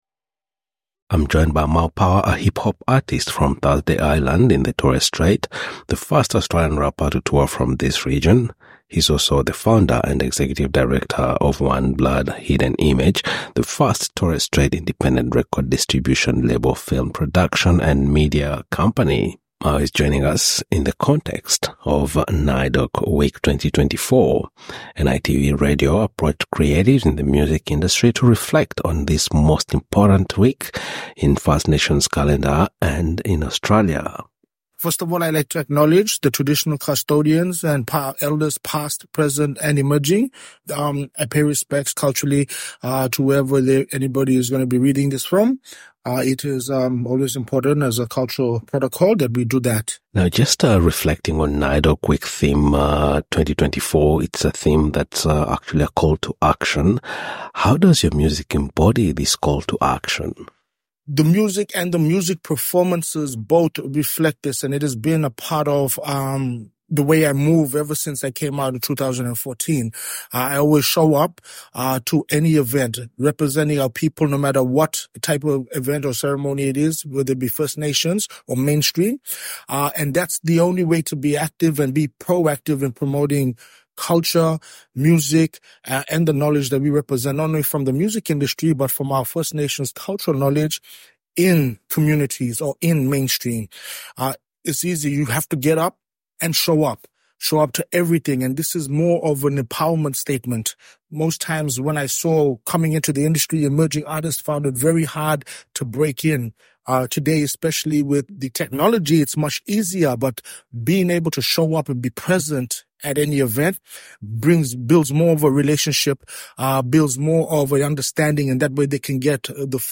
SBS NITV Radio